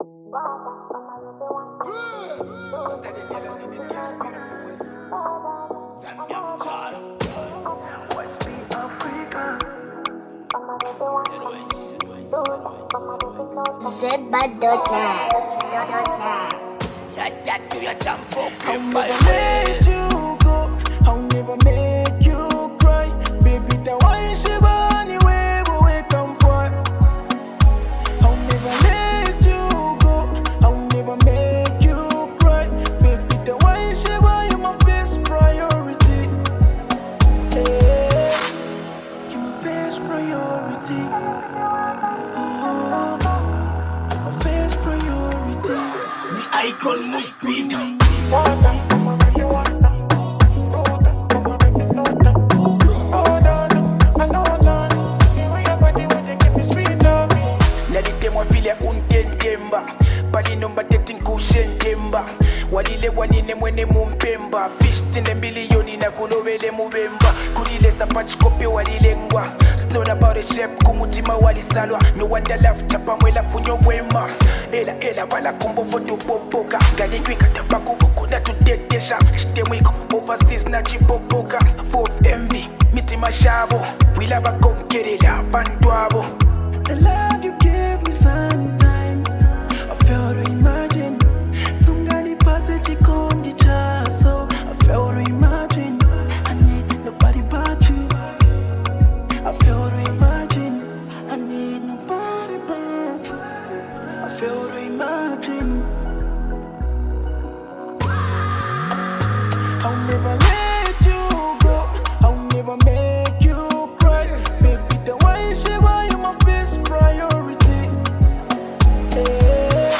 Genre: Dancehall
Category: Zambian Music